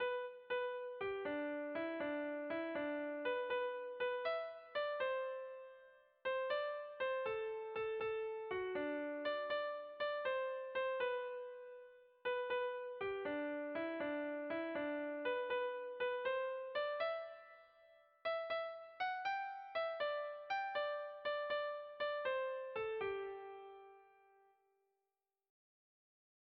Sentimenduzkoa
Zortziko txikia (hg) / Lau puntuko txikia (ip)
A-B-A2-C